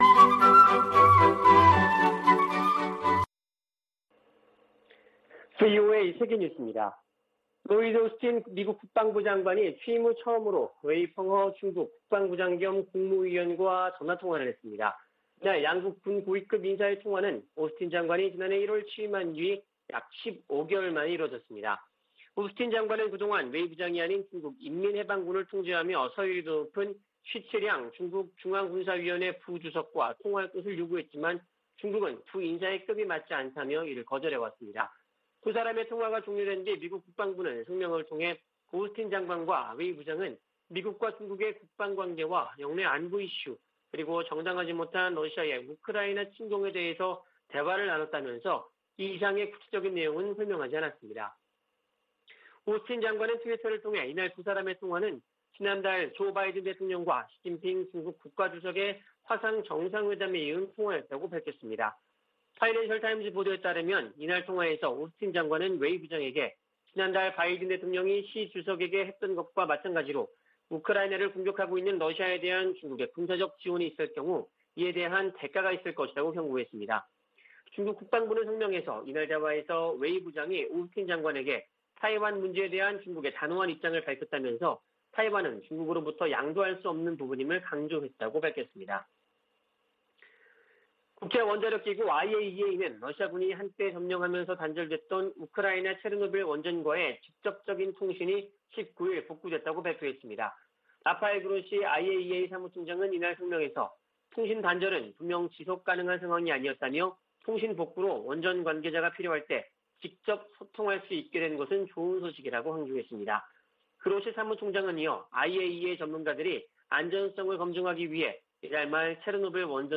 VOA 한국어 아침 뉴스 프로그램 '워싱턴 뉴스 광장' 2022년 4월 21일 방송입니다. 미 국무부는 북한이 도발을 계속하면 상응 조치를 이어갈 것이라고 경고했습니다.